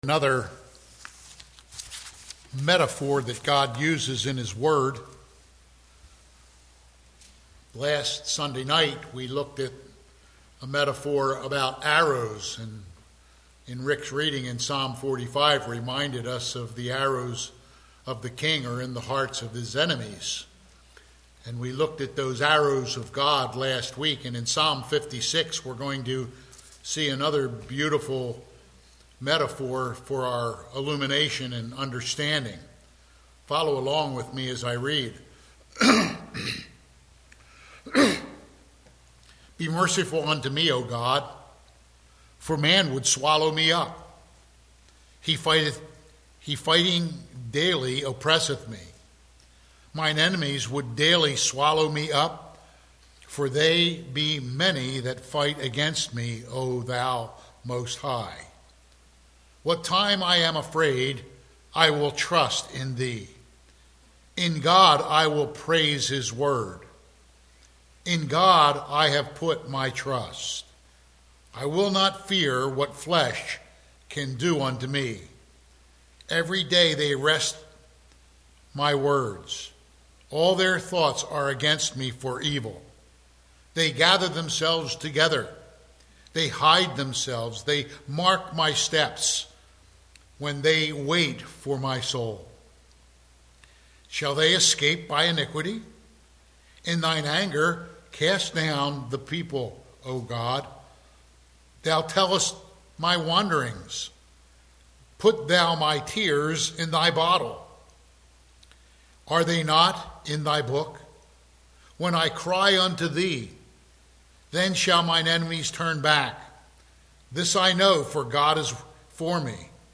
Passage: Psalm 56:1-13 Service Type: Sunday PM « January 14